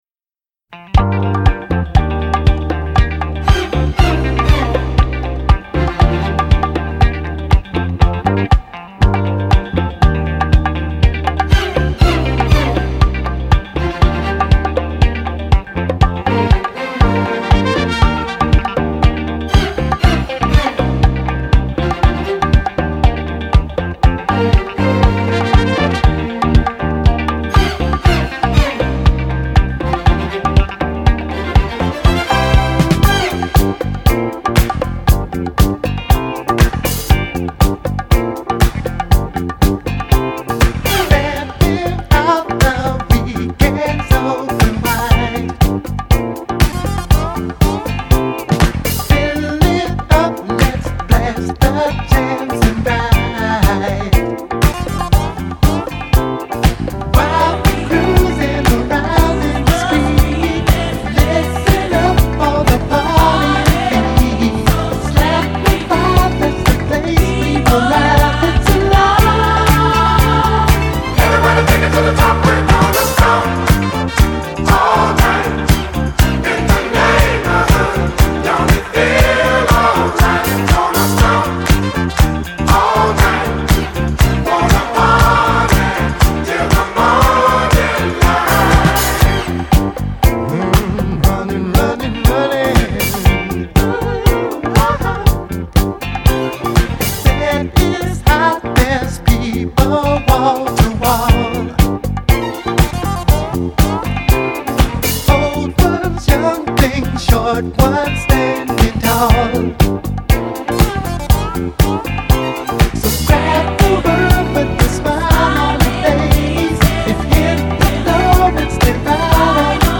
groovy R&B/soul songs